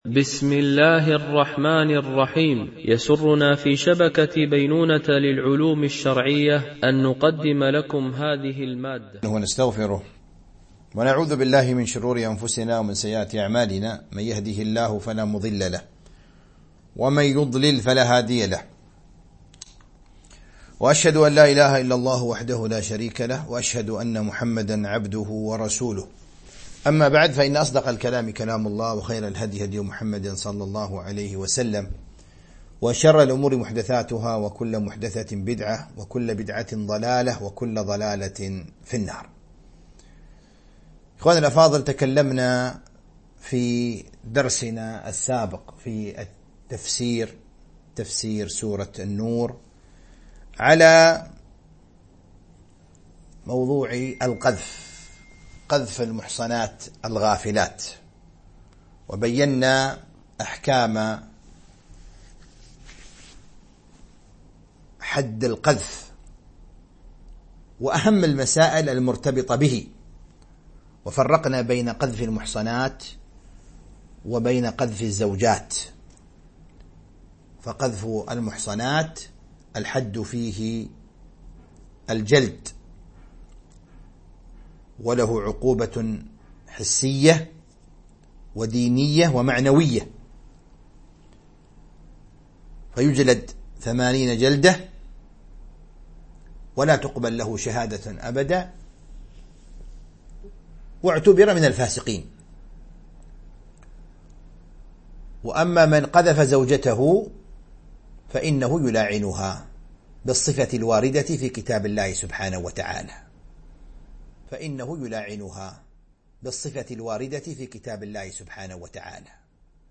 سلسلة محاضرات
MP3 Mono 22kHz 32Kbps (CBR)